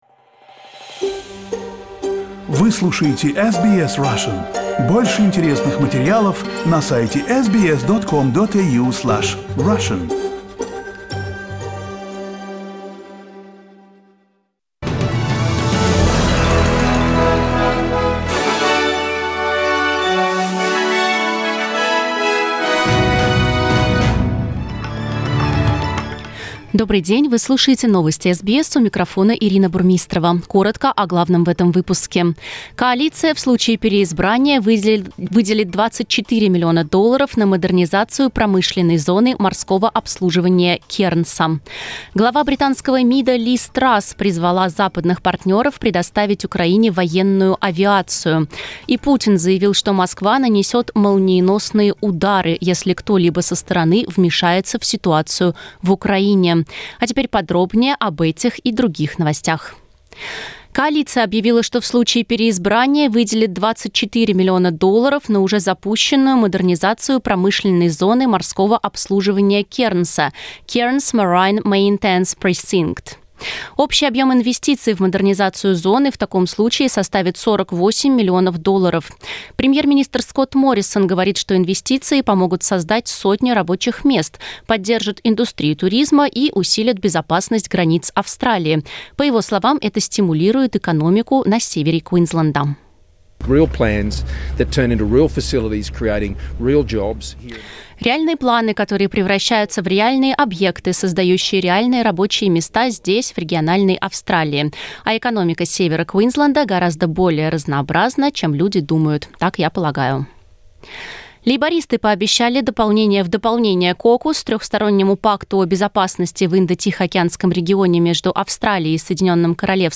Listen to the top Australian and world news by SBS Russian.